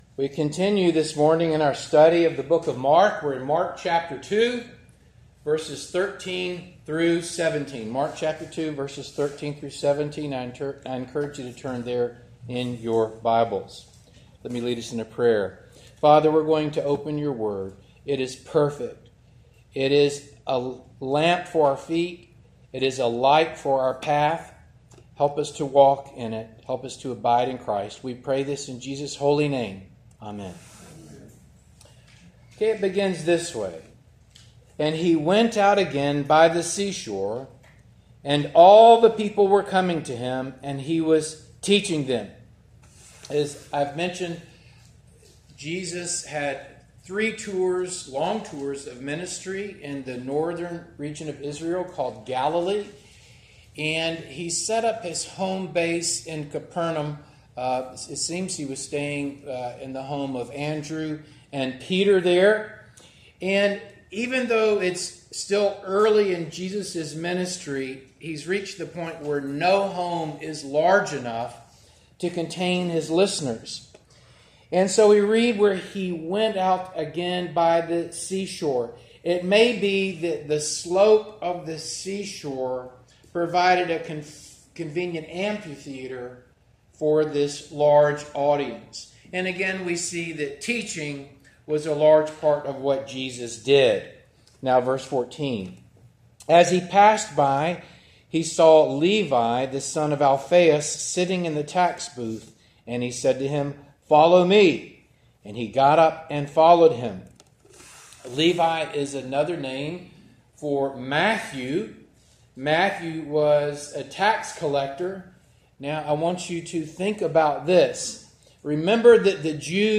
Mark Passage: Mark 2:13-17 Service Type: Morning Service Download Files Bulletin « “‘Son